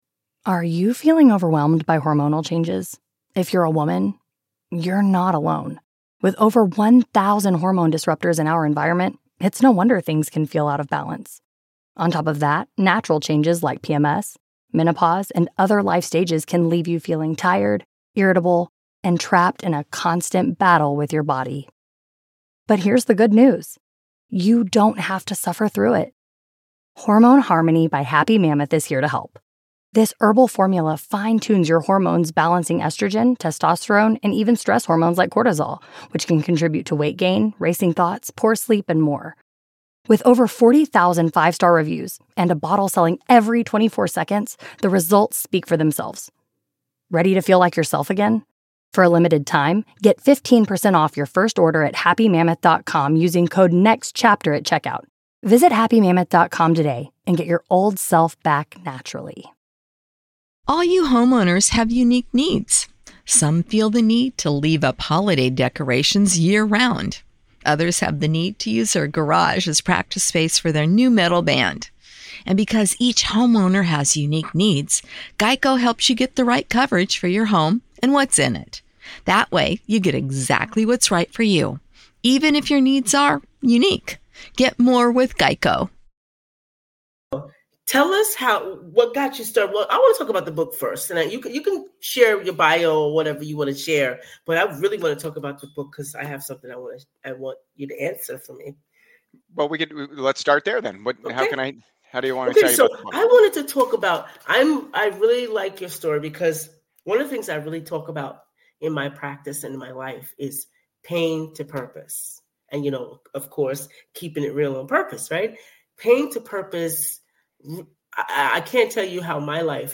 🌟 Join Us for an Inspiring Conversation! 🌟